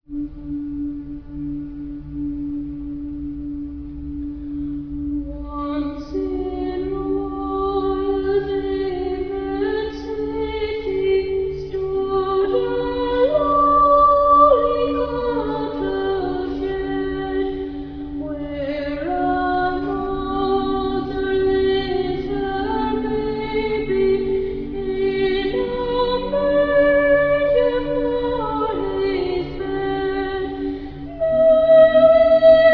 boy soprano
girl soprano